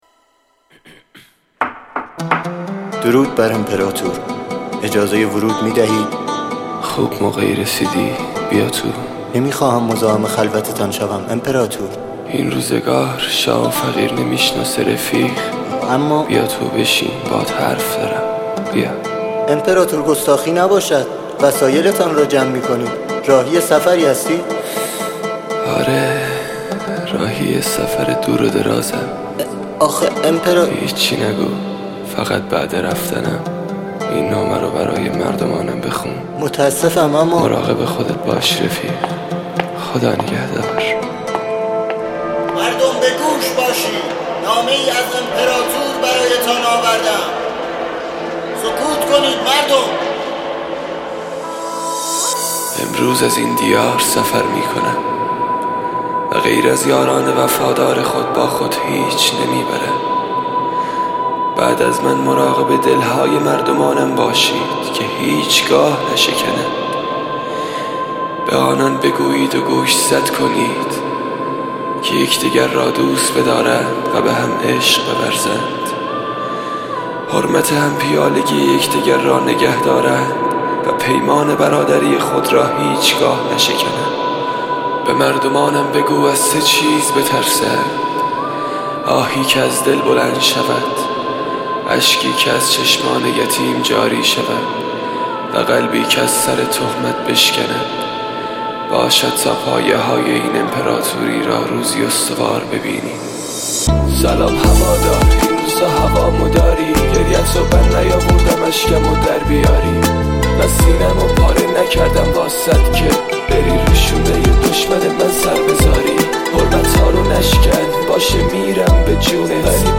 دیسلاو